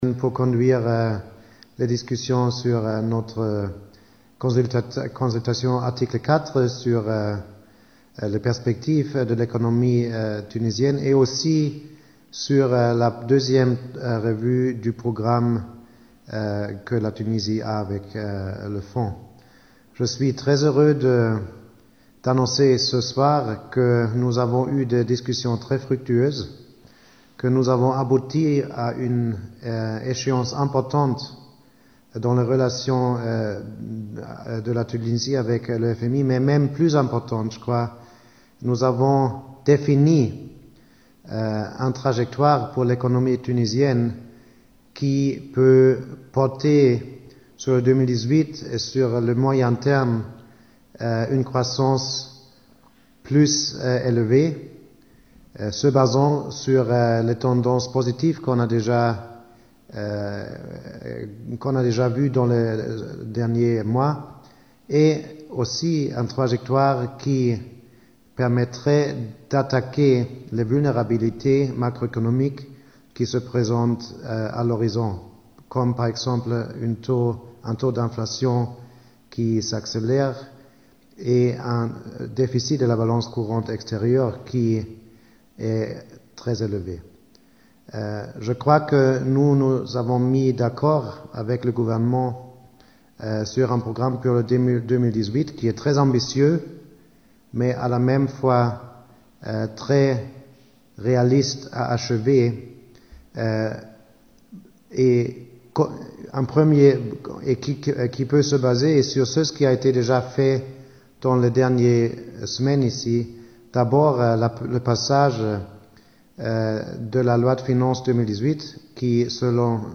وأكد المسؤول في صندوق النقد الدولي، في ندوة صحفية عقدت عشية اليوم الأربعاء، أن المحادثات التي أجرتها البعثة مع المسؤولين التونسيين كانت "مثمرة" وأفضت إلى تحديد مسار للاقتصاد التونسي لسنة 2018، لتحقيق نسبة نمو أرفع على المستوى المتوسط، والحد من عجز الموازنة ونسبة التضخم.
ممثل صندوق النقد الدولي